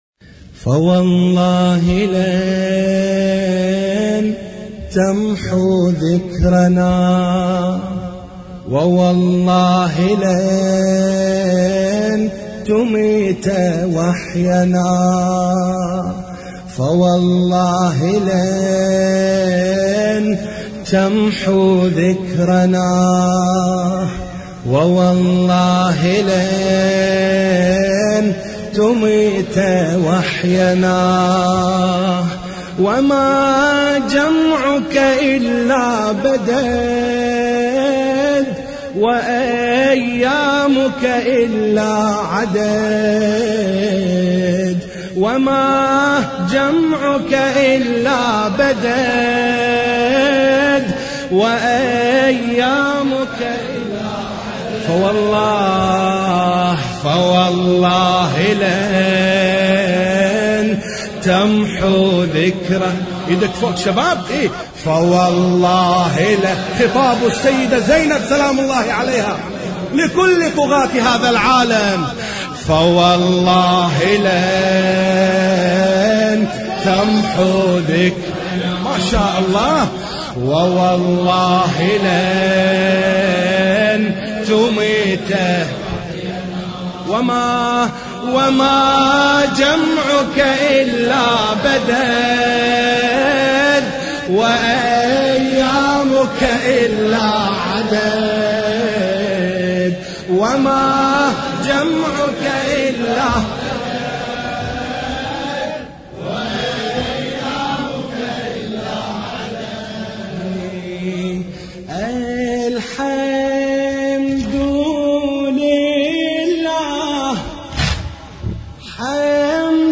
مراثي زينب الكبرى (س)